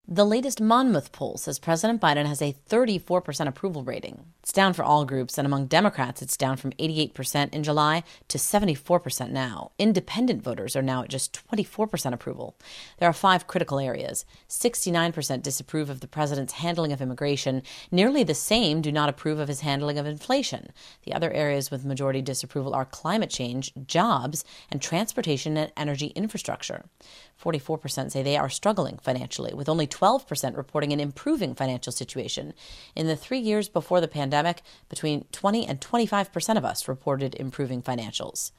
Two polls released this week cast a long shadow over the president’s reelection prospects. The first is from Monmouth University.